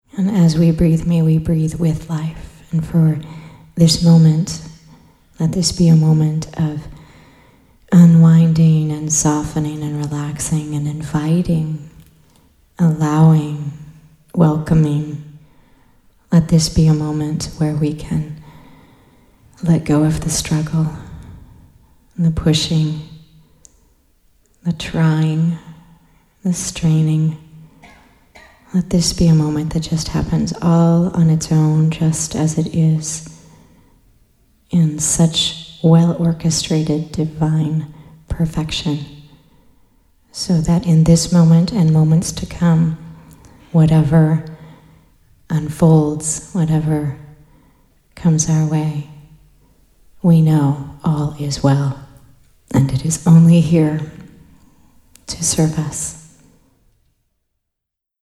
Please join us for these 14 hours of nourishing, uplifting, often hilarious, peaceful, and powerful teachings from this miraculous 5-day retreat.
a rejuvenating guided meditation and the chanting of OM. This supports the unraveling of the overstimulated nervous system and the lengthening of your attention span, so a more expanded heart-centered consciousness can dawn within you.